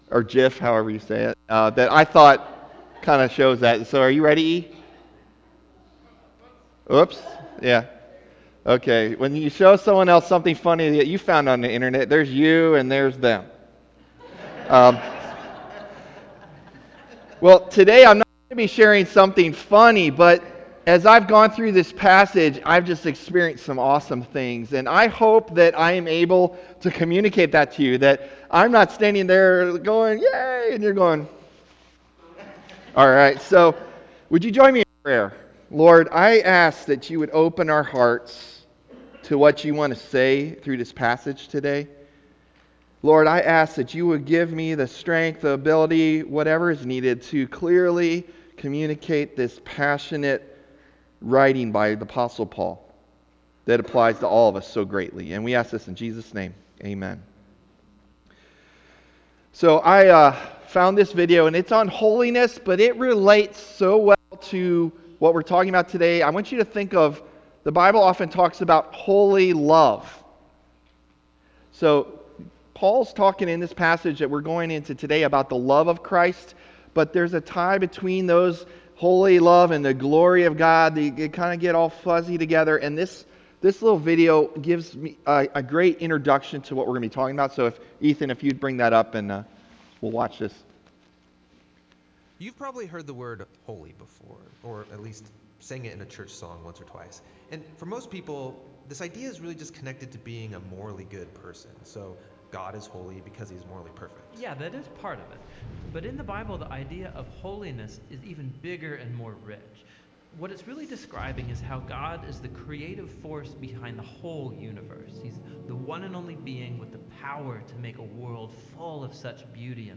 October 22 Sermon | A People For God